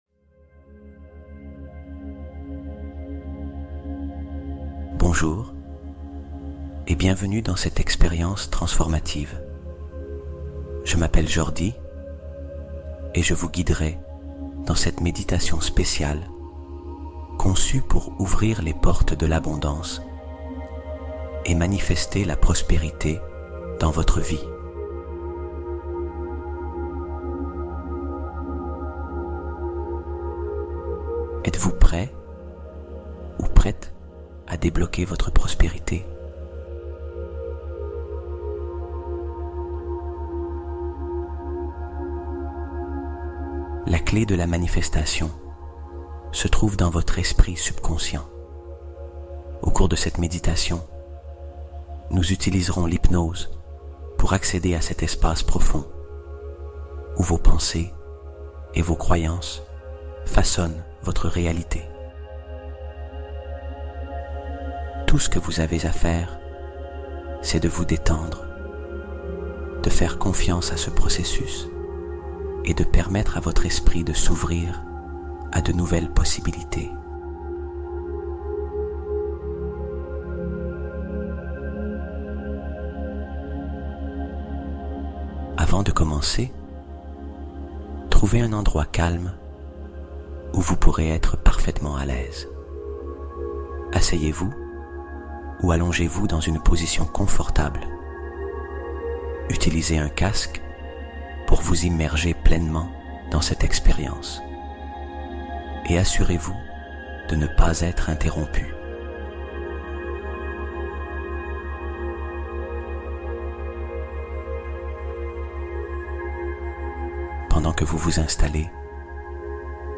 Ouvre Les Portes de L'Abondance Infinie : Hypnose Pour Manifester La Prospérité Immédiate